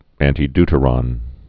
(ăntē-dtə-rŏn, -dy-, ăntī-)